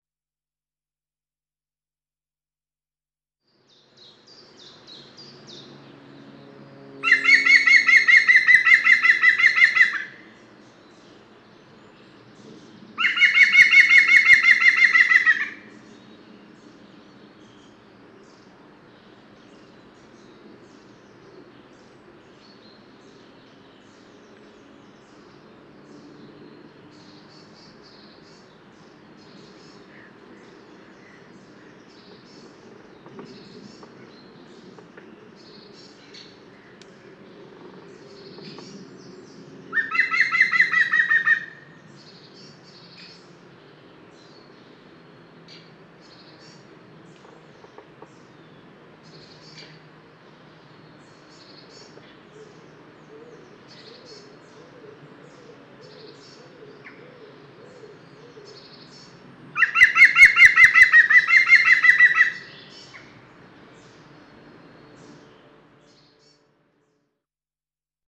Outdoor Meeting: Dawn Chorus - Rutland Natural History Society